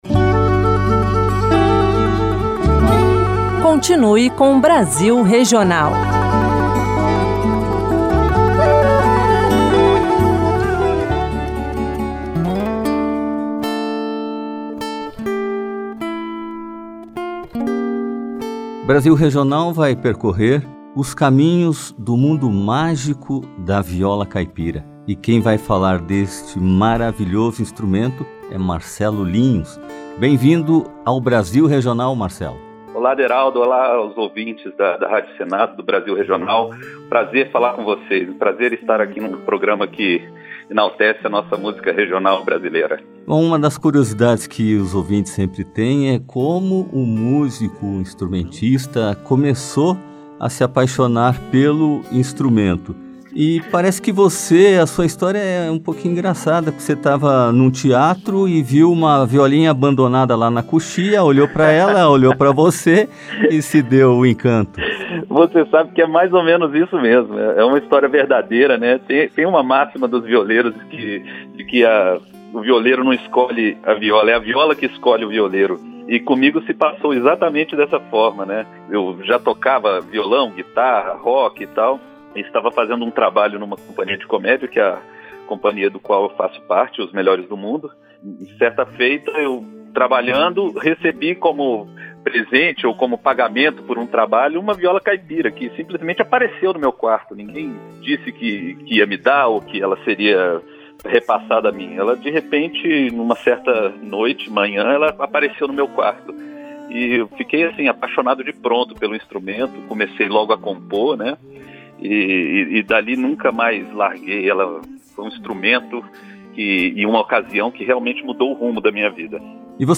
Música Brasileira